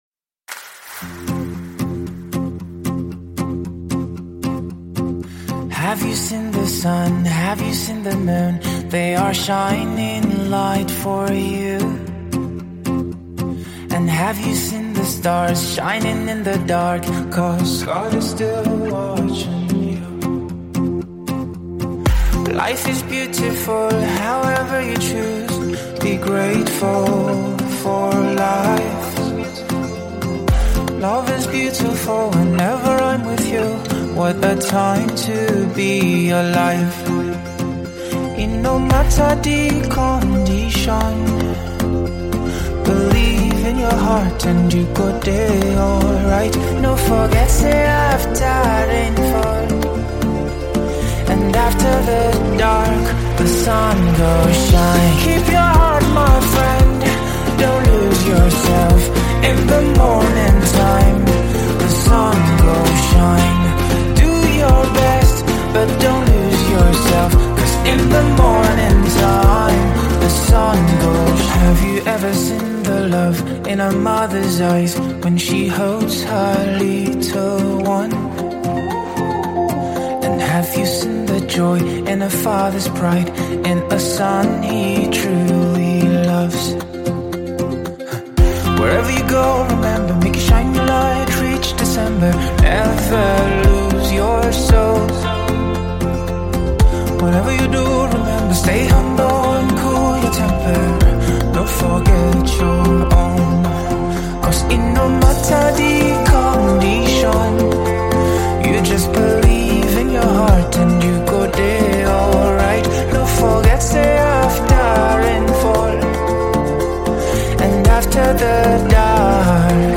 alternative singer